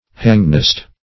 Meaning of hangnest. hangnest synonyms, pronunciation, spelling and more from Free Dictionary.
hangnest.mp3